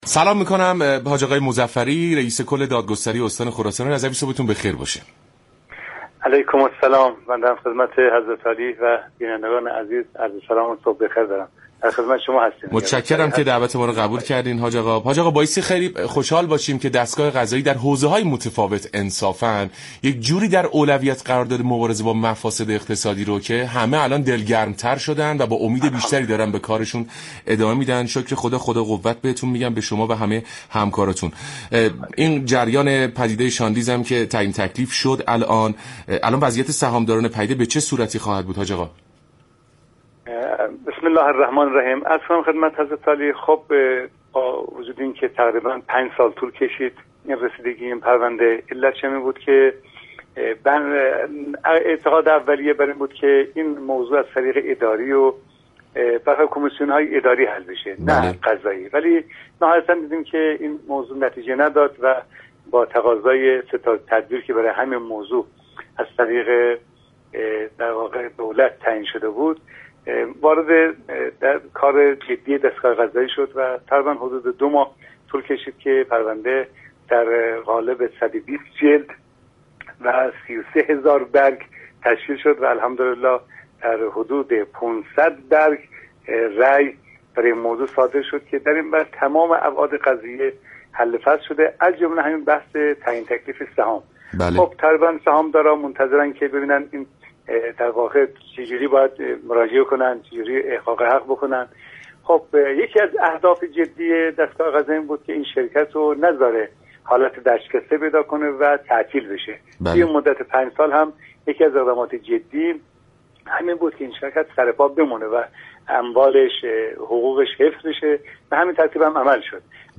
«حجت الاسلام علی مظفری» رئیس كل دادگستری استان خراسان رضوی در برنامه «سلام صبح بخیر» رادیو ایران گفت : سهام ها بیش از 5 برابر هم شده است.